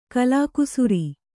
♪ kalākusuri